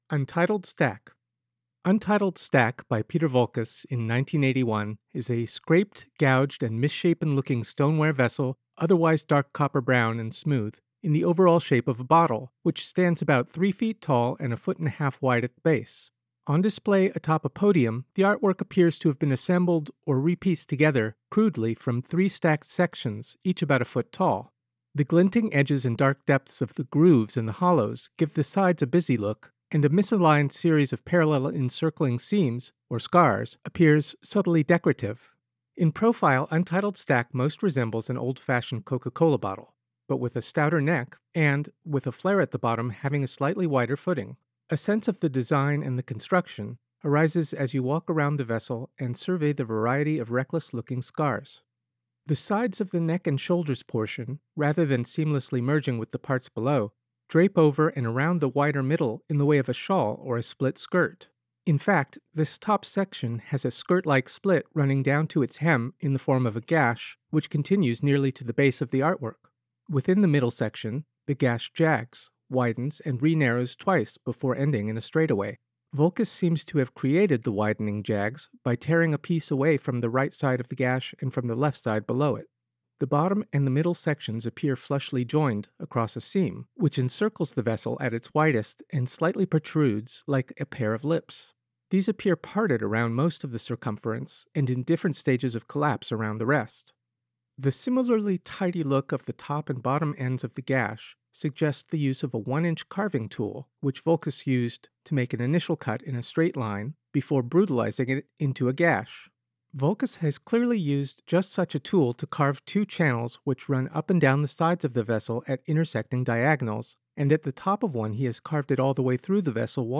Audio Description (04:24)